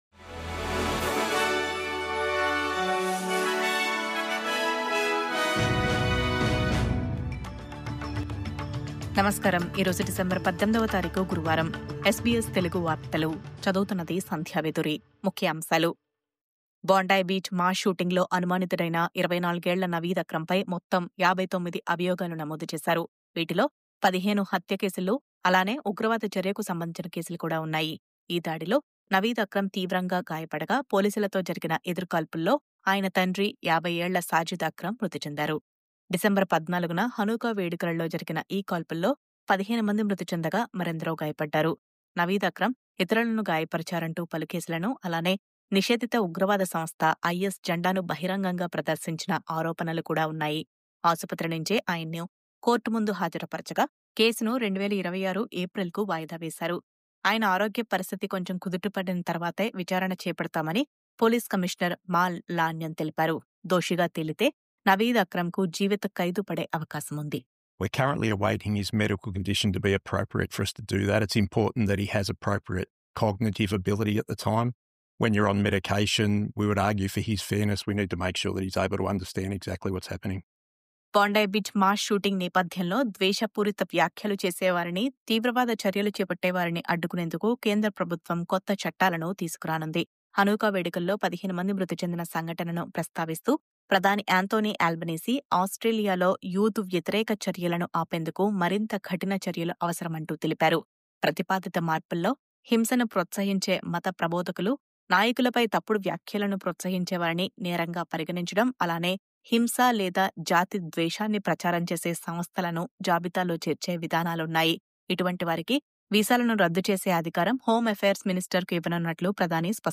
News update